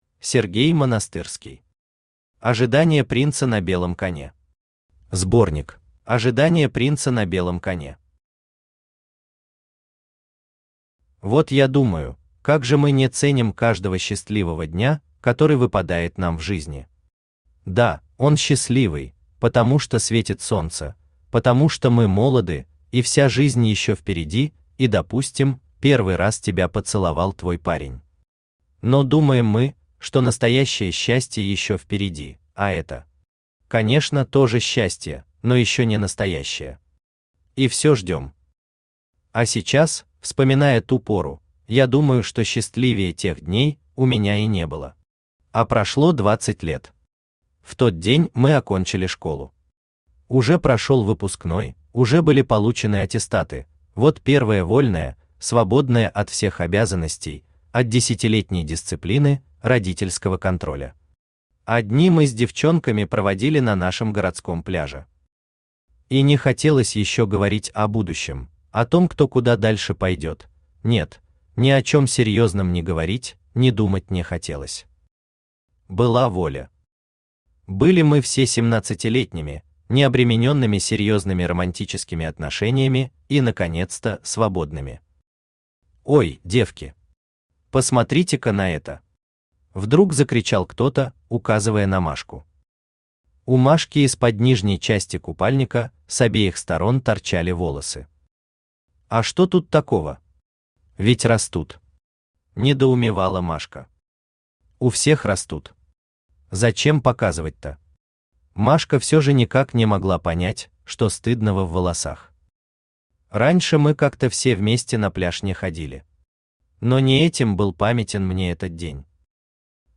Аудиокнига Ожидание принца на белом коне. Сборник | Библиотека аудиокниг
Сборник Автор Сергей Семенович Монастырский Читает аудиокнигу Авточтец ЛитРес.